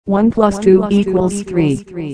Roboterstimmen Mary, Mike...